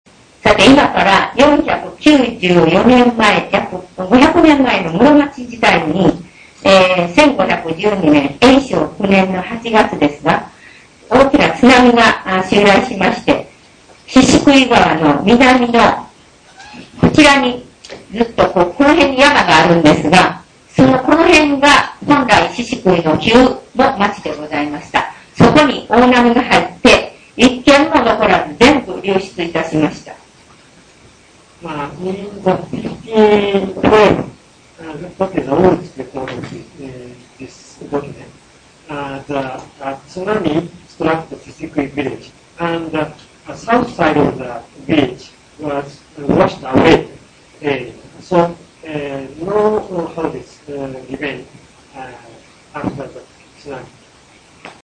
Field Trip